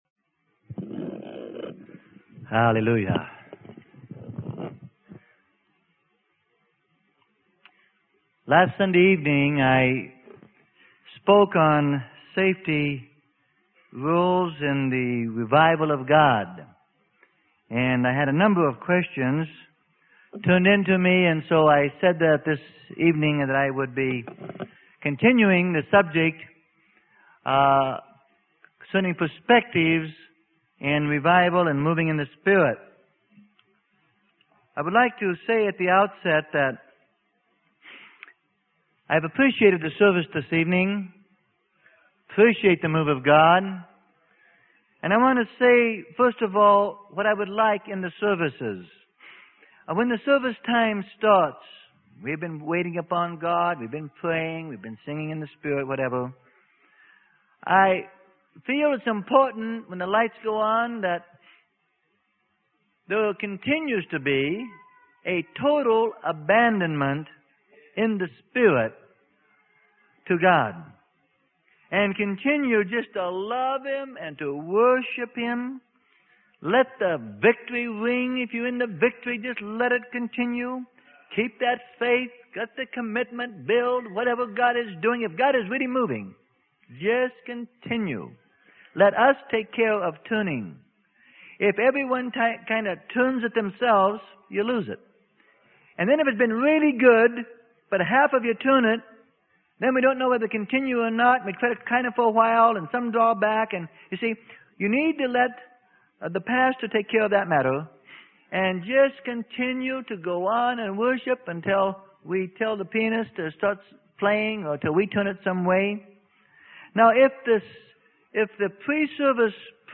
Sermon: Spiritual Safety Rules for Revival Times - Part 2 - Freely Given Online Library